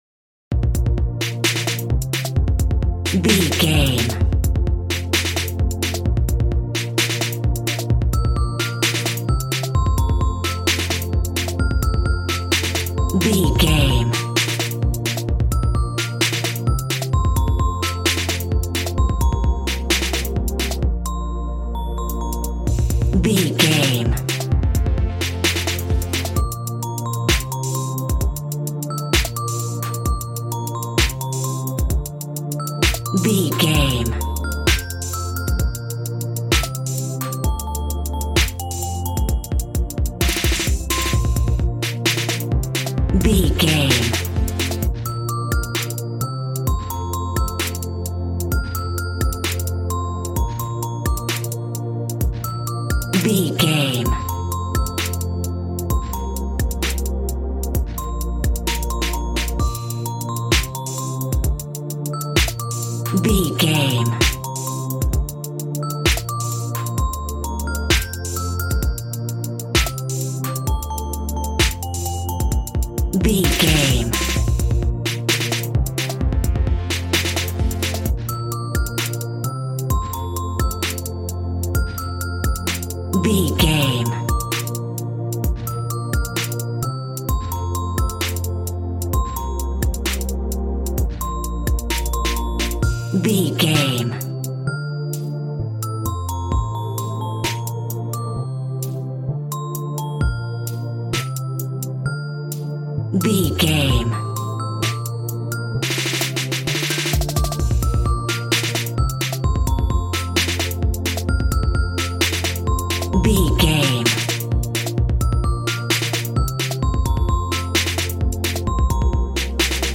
Aeolian/Minor
B♭
Fast
groovy
synthesiser
drums
piano